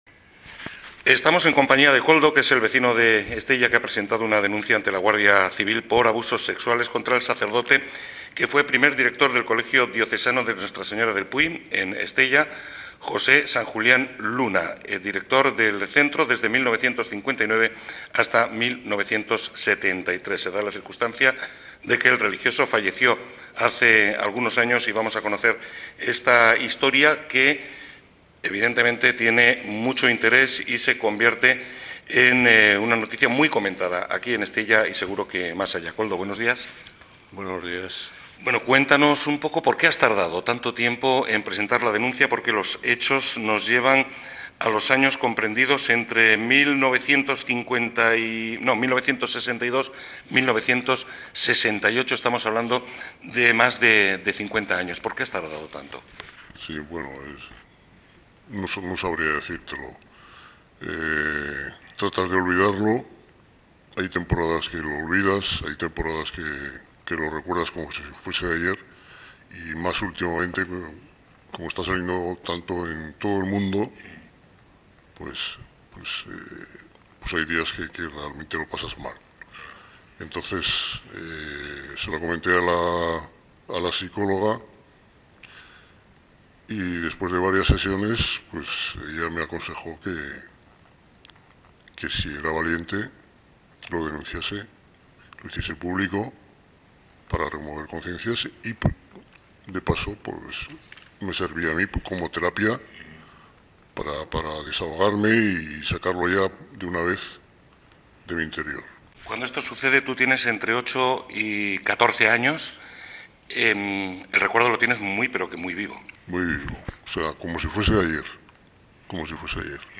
En la entrevista se ha utilizado un nombre figurado, y se ha modulado la voz de esta persona con el objetivo de garantizar su anonimatos.
Asegura que en Estella se puede hablar de muchos casos similares en referencia a lo ocurrido en el colegio del Puy con este y otros profesores a lo largo de la historia. Este exalumno del colegio diocesano vería con buenos ojos la creación de un colectivo o asociación de afectados por lo que anima a que se presenten las correspondientes denuncias. A continuación puedes escuchar la entrevista completa.